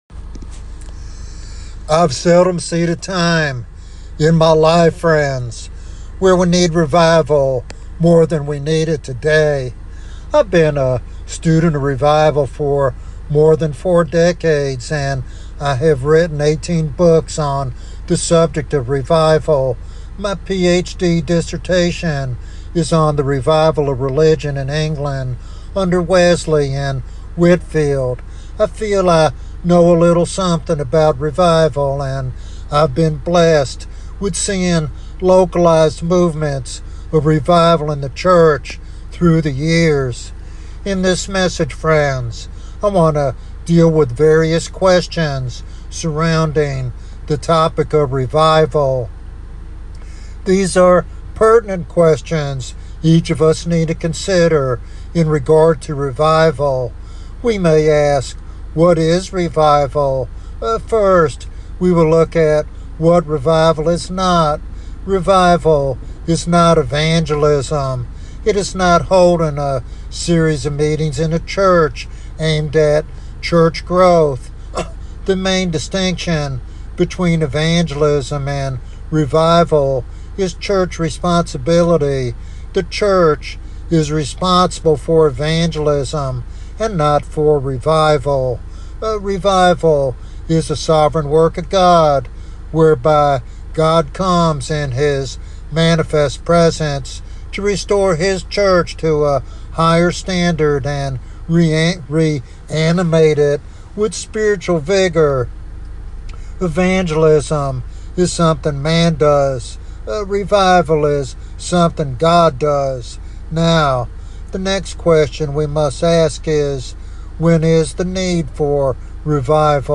In this teaching sermon